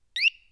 Door_Alarm.wav